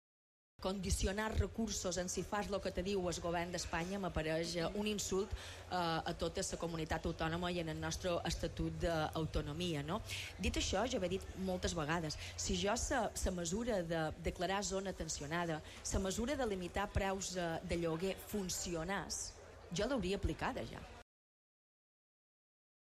En l'entrevista al programa 'Al Dia' d'IB3 Ràdio, la presidenta de les Balears afirma que l'executiu espanyol s'hi ha inspirat a l'hora de dissenyar l'estratègia turística estatal
Ho ha explicat en una entrevista a IB3 Ràdio , amb motiu de la fira del Dijous Bo d’Inca.